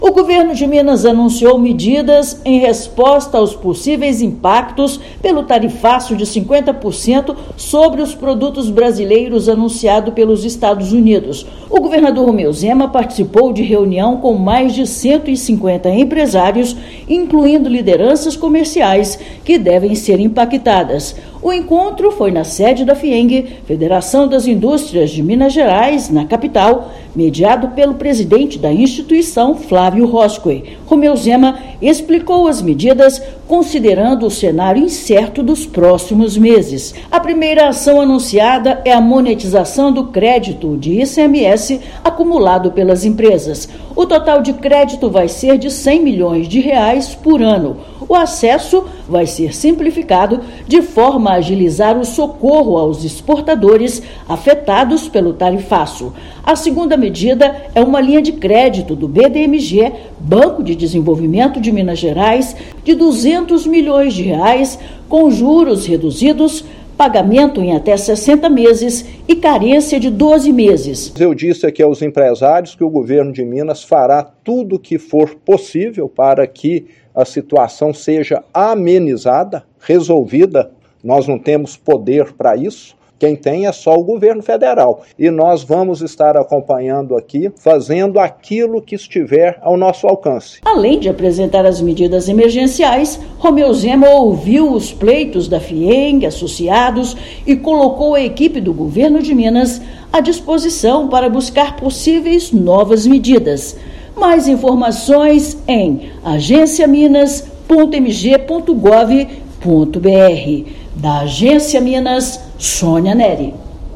Chefe do Executivo estadual anunciou a monetização do crédito do ICMS e uma linha específica junto ao BMDG durante reunião com empresários de setores produtivos. Ouça matéria de rádio.